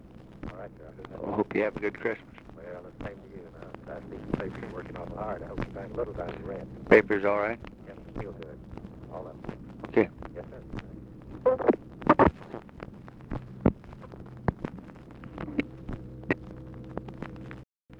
Conversation with AUGUST BUSCH, December 27, 1963
Secret White House Tapes